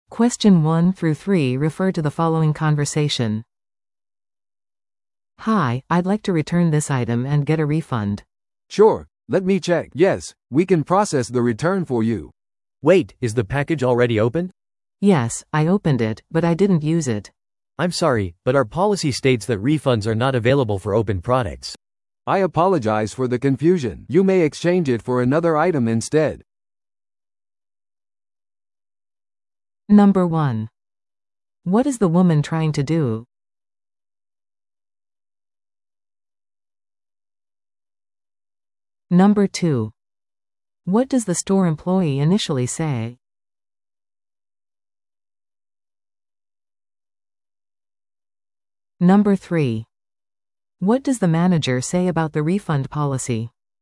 TOEICⓇ対策 Part 3｜返品・交換ポリシーに関する会話 – 音声付き No.26